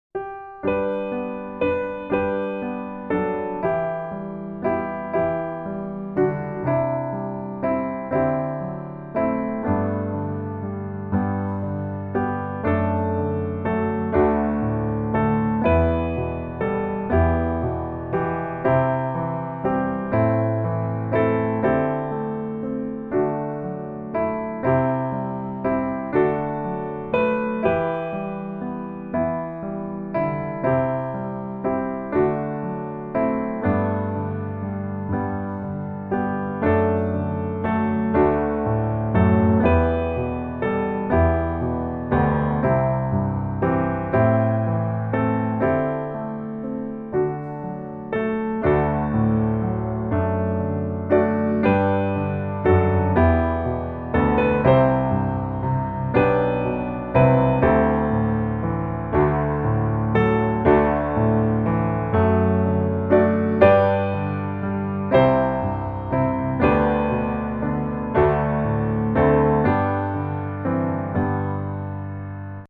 G Majeur